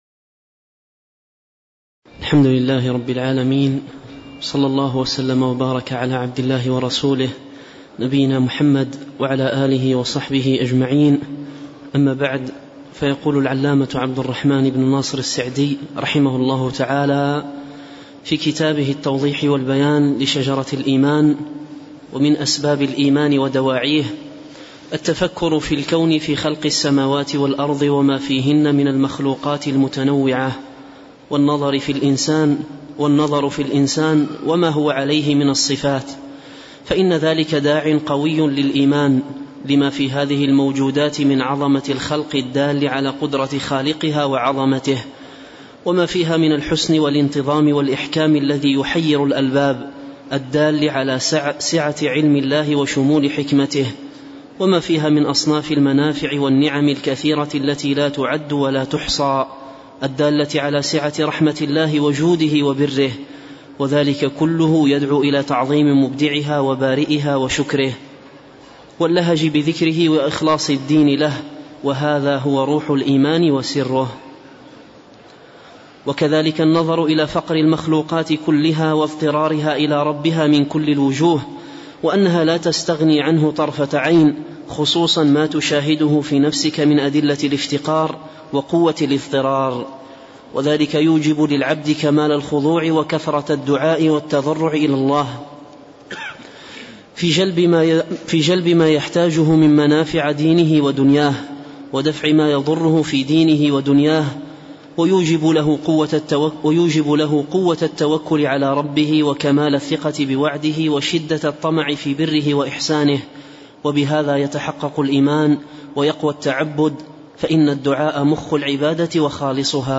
شرح التوضيح والبيان لشجرة الإيمان الدرس 11